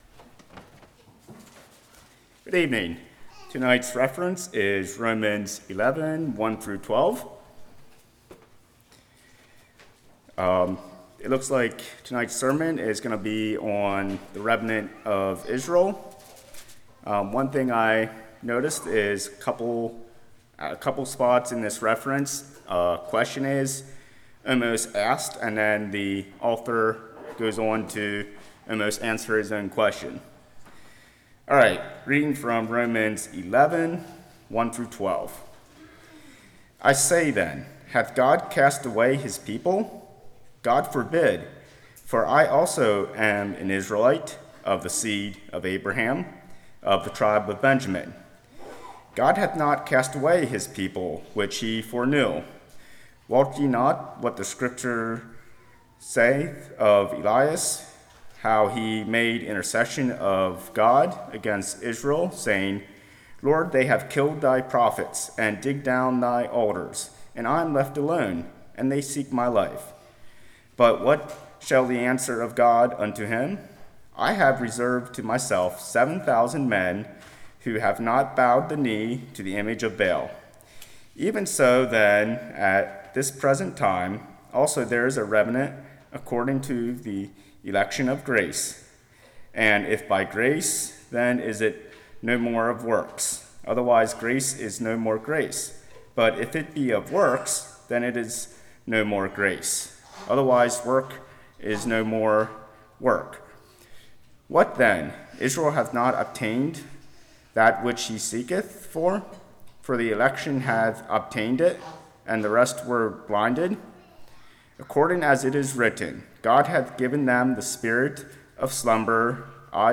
Romans 11:1-12 Service Type: Evening Did God Cast Israel Aside?